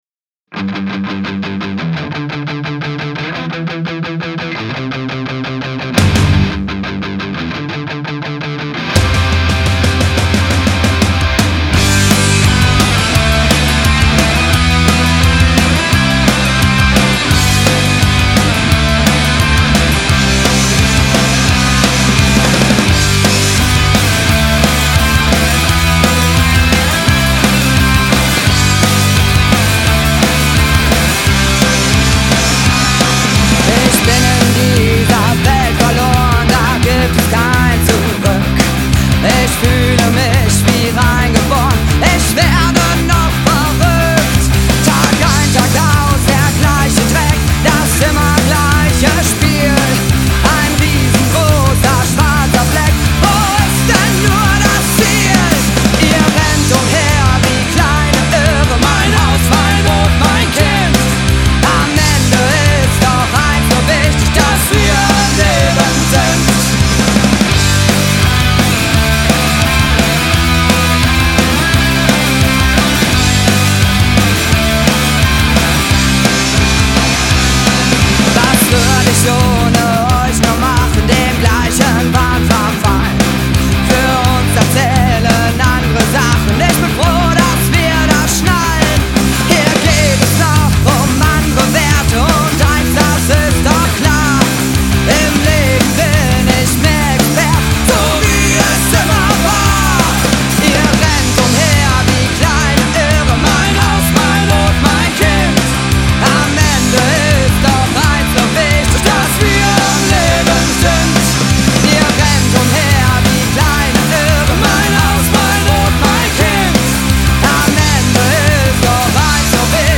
Gesang
Gitarre und Gesang
Bass
Drums